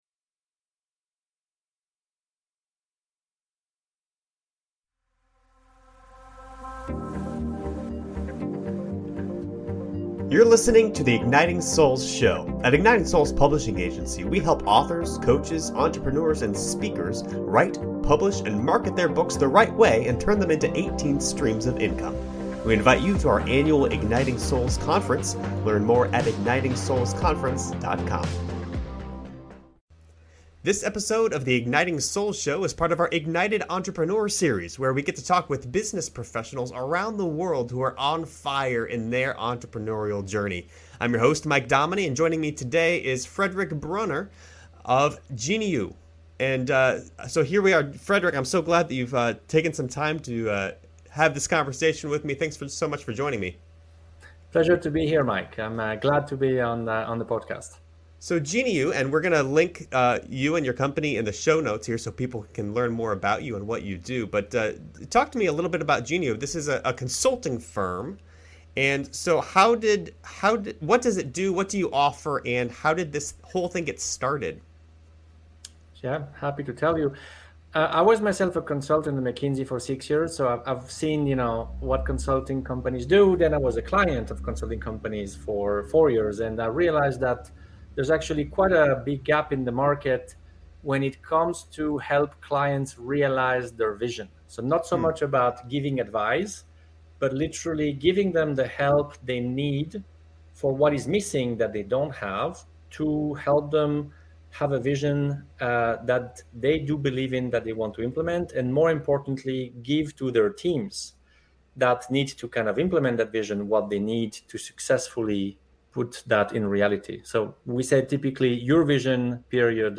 He also explains the importance of "Wow!" when it comes to client relations and culture-building. You'll find yourself saying "Wow" in this power-packed conversation